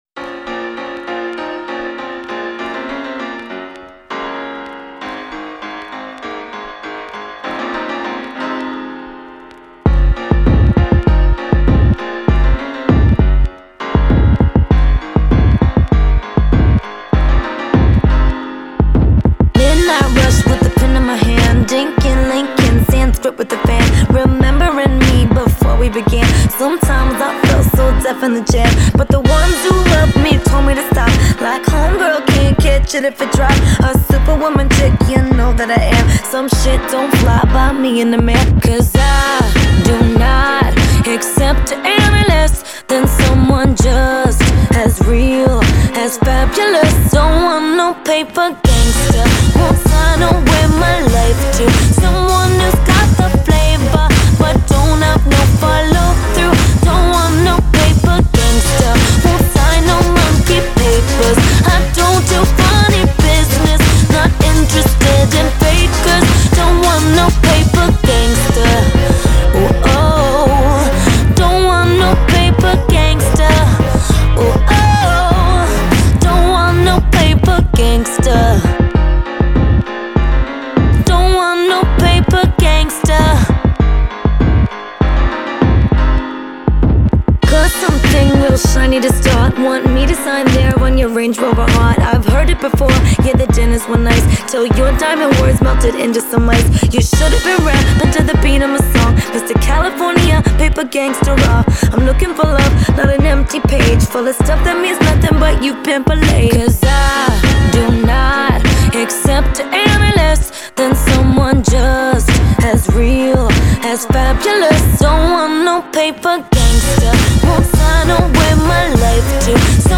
Категория : Поп